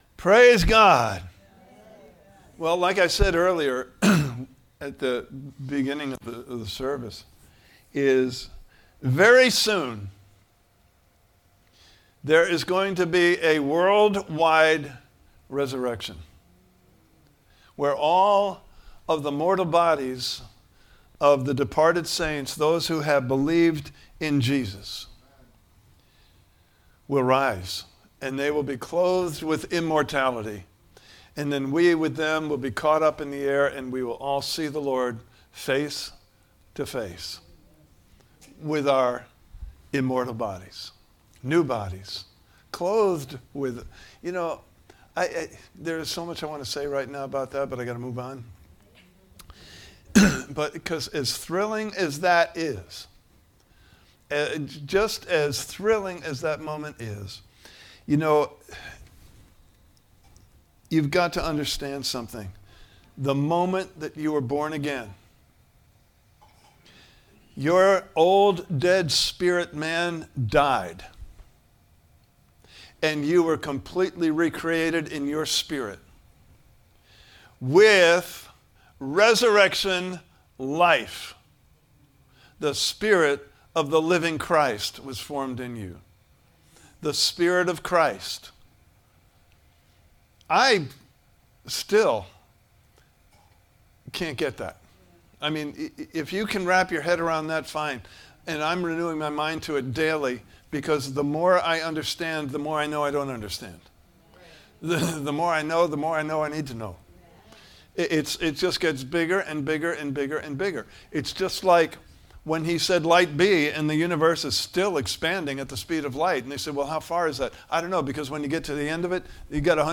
Service Type: Sunday Morning Service « Good Friday (Full) Service – April 3, 2026 Part 1: The Spirit of Resurrection Power!